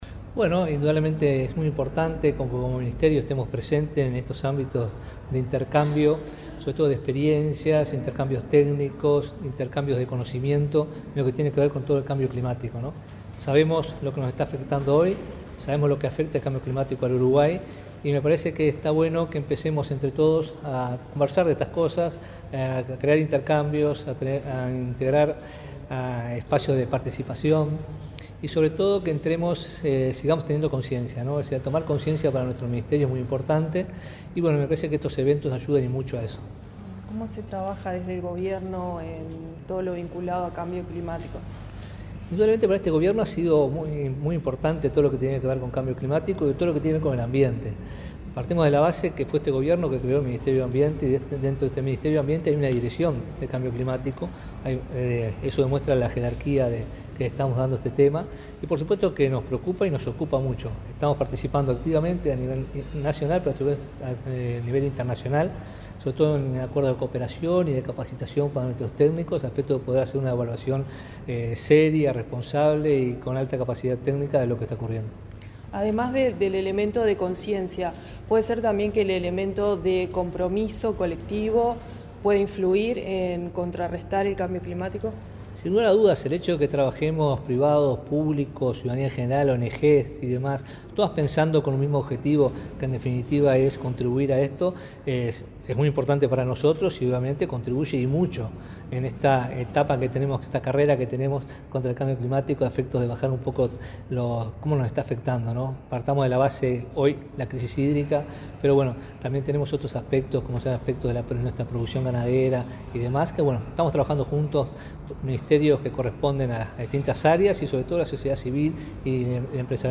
Entrevista al ministro de Ambiente
Entrevista al ministro de Ambiente 24/07/2023 Compartir Facebook X Copiar enlace WhatsApp LinkedIn El ministro de Ambiente, Robert Bouvier, dialogó con Comunicación Presidencial luego de participar, en Montevideo, en la presentación de la primera edición del foro América Verde.